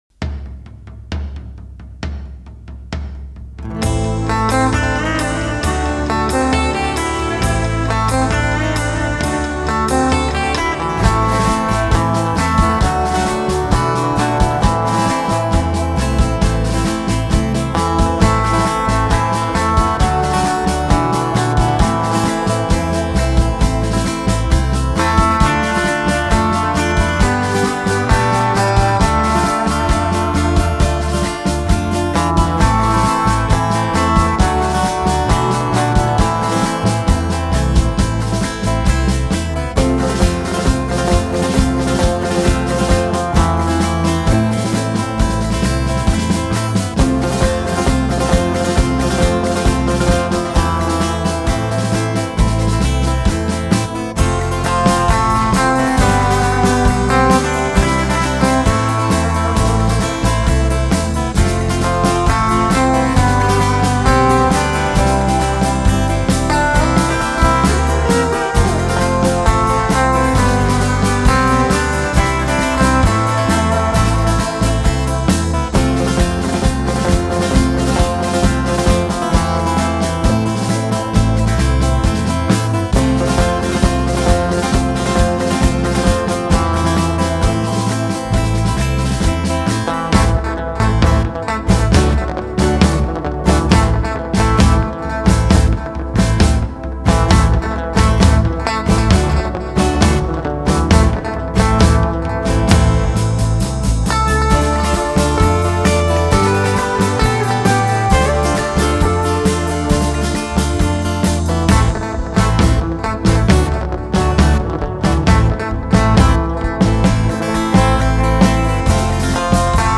Guitar instrumental